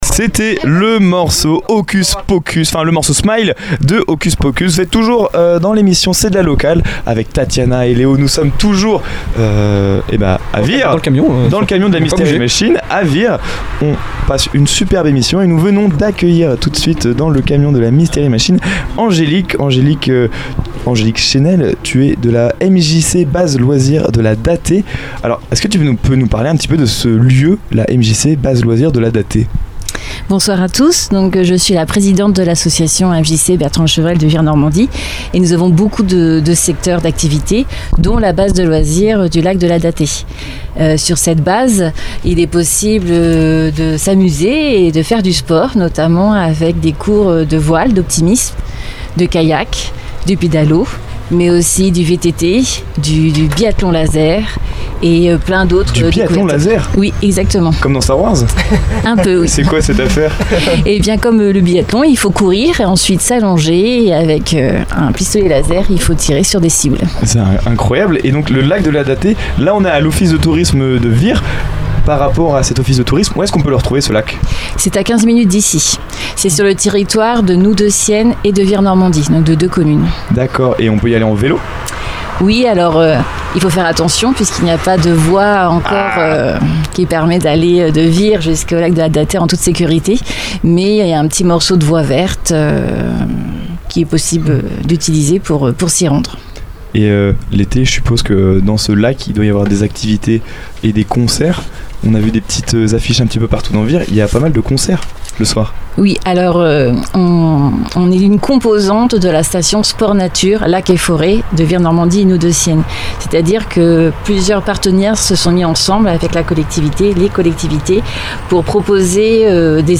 Une interview dynamique et conviviale qui met en lumière un lieu incontournable pour profiter de la nature, du sport et des moments de partage à Vire Normandie.